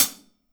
Index of /kb6/Akai_MPC500/1. Kits/Amb Rm Kit
KUMA HHcl mx1.WAV